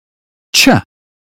uk_phonetics_sound_cheese_2023feb.mp3